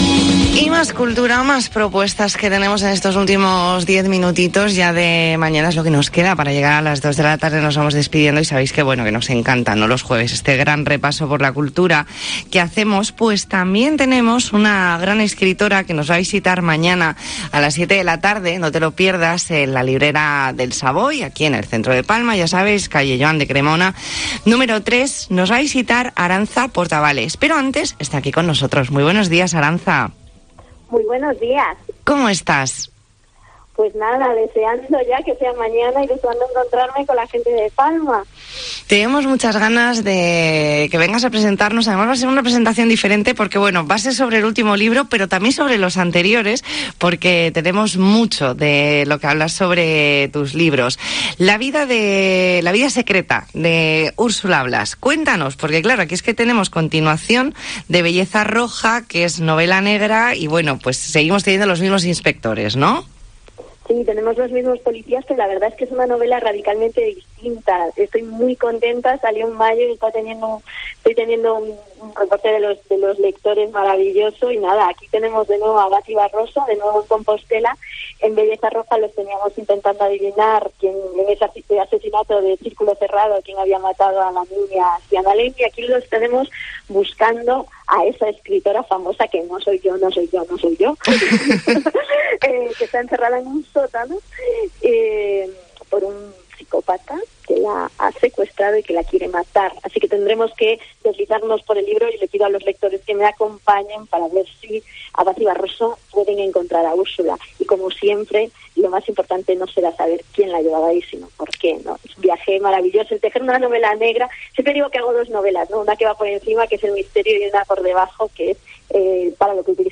Entrevista en La Mañana en COPE Más Mallorca, jueves 14 de octubre de 2021.